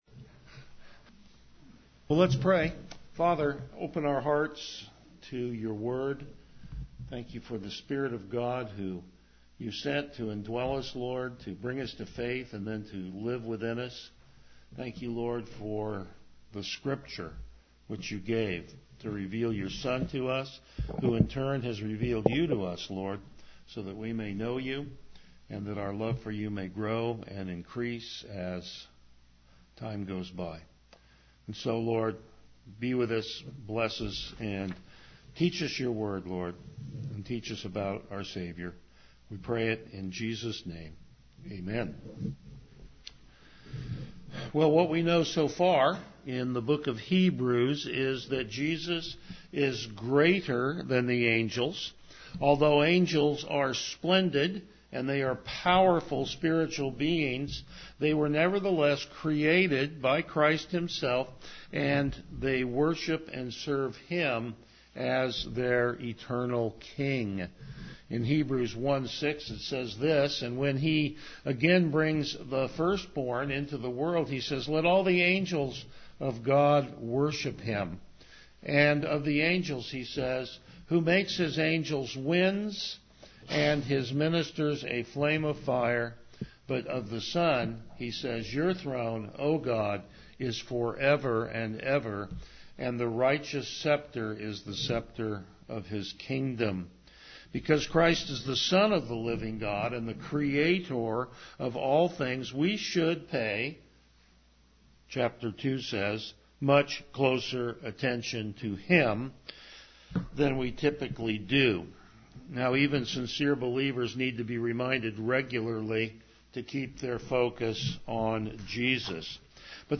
Service Type: Morning Worship
Verse By Verse Exposition